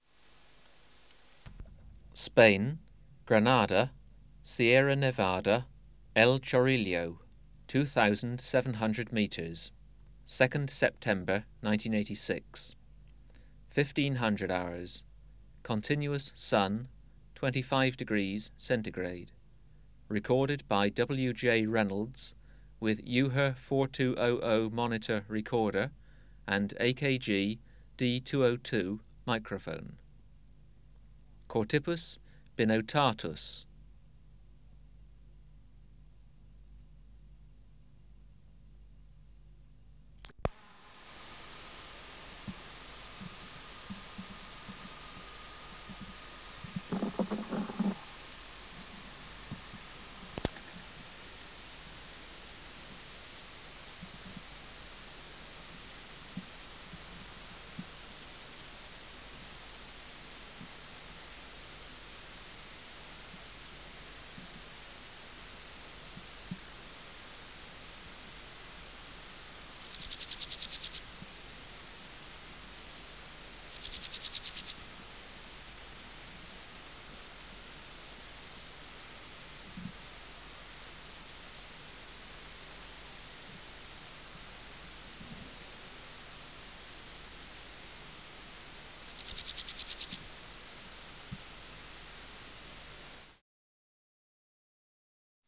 428:15 Chorthippus binotatus (642r4) | BioAcoustica
Recording Location: Europe: Spain: Granada, Sierra Nevada, El Chorrillo, 2700m
Air Movement: Slight breeze
Substrate/Cage: On grass
Microphone & Power Supply: AKG D202 (LF circuit off) Distance from Subject (cm): 10